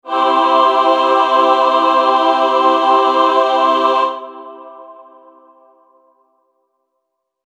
plt.plot(ahhh); # notice this one has two plots because it is a stereo signal
ahhh.wav